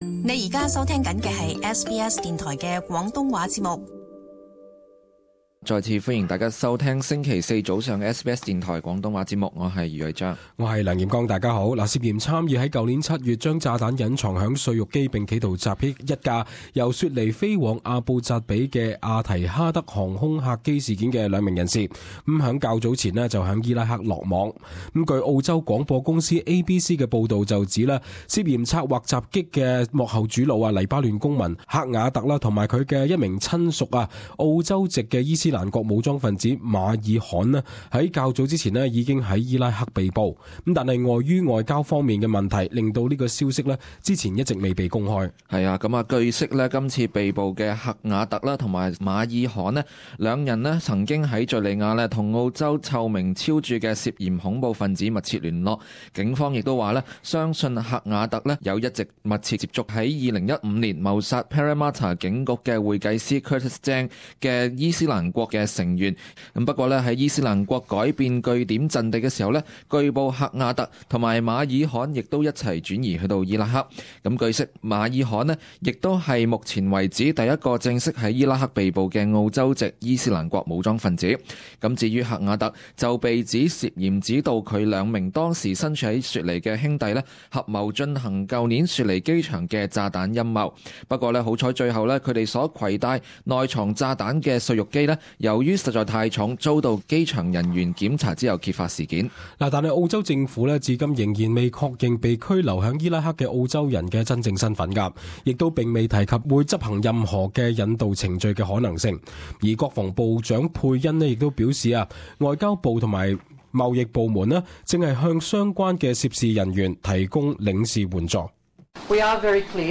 【時事報導】去年雪梨機場企圖恐襲案兩名疑犯於伊拉克落網